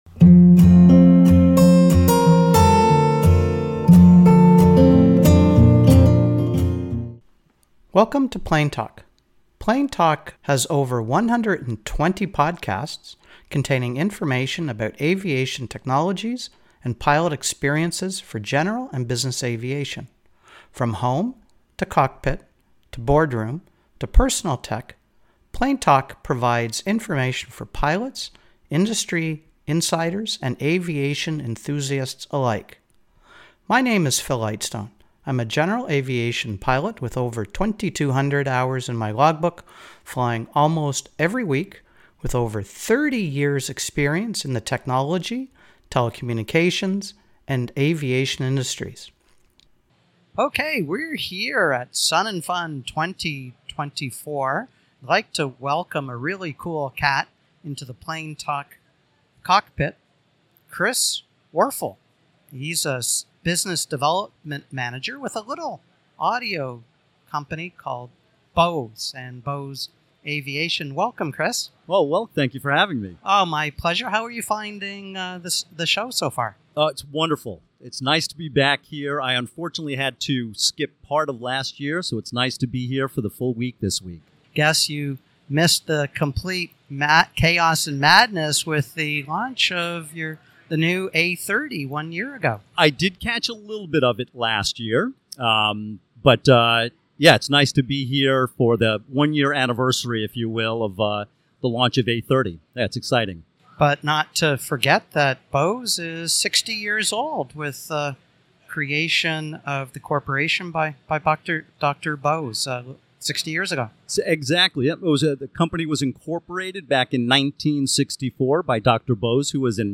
Recorded at the Bose Pavillion at SUNnFUN 2024